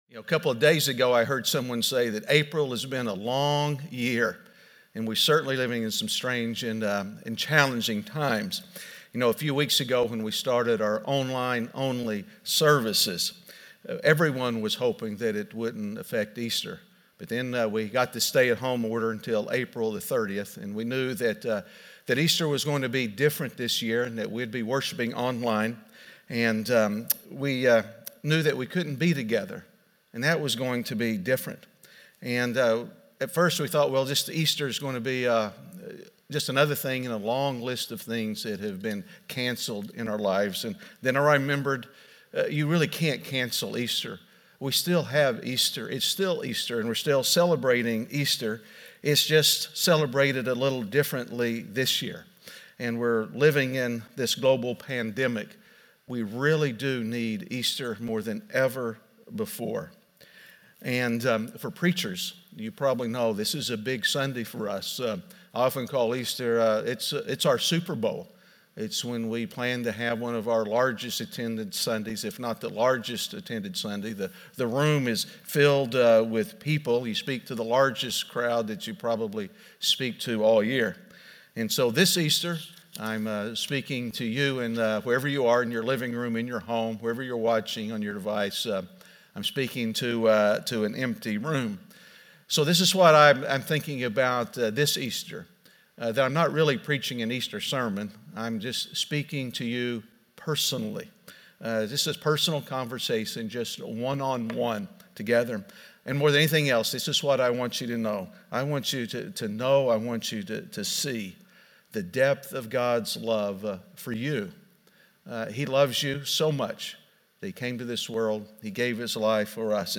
Bethany Easter Finding Peace In A Pandemic (Week 4) - Sermon.mp3